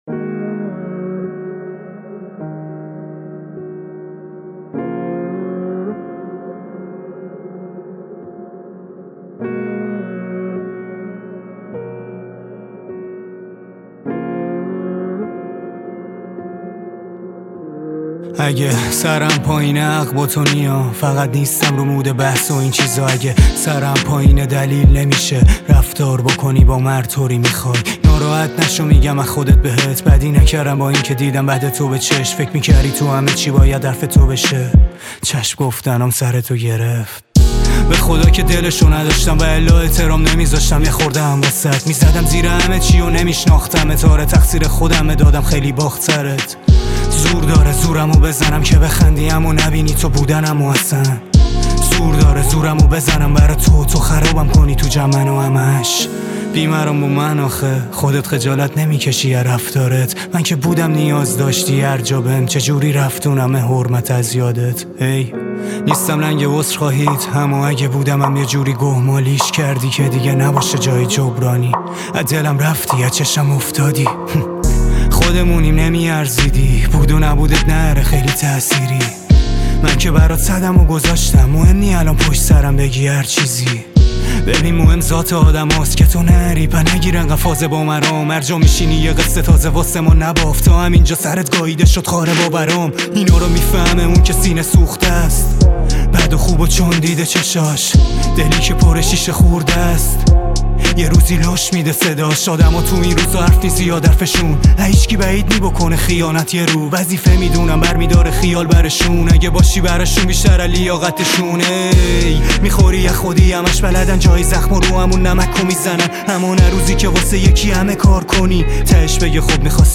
آهنگ جدید عاشقانه و احساسی